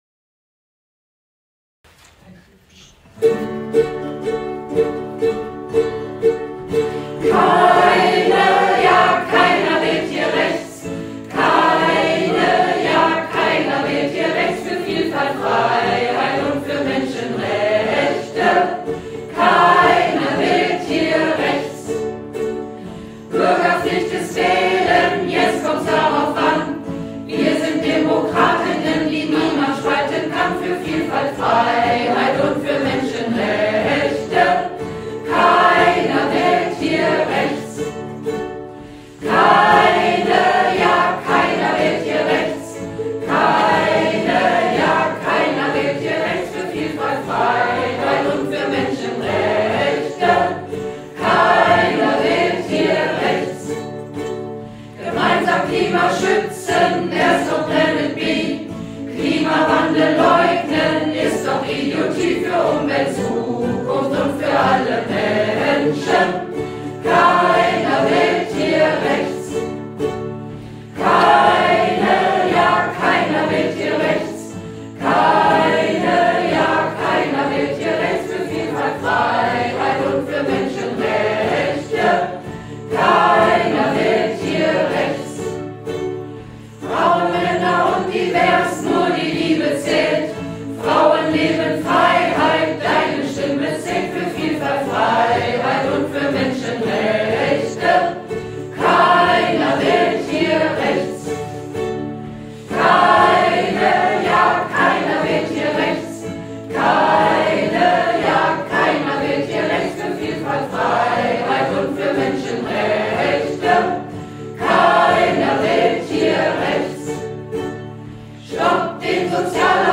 GEMA ist hierfür nicht fällig, da es sich bei der Originalmelodie (“We shall not be moved”) um einen alten Gospel handelt, der nicht mehr geschützt ist.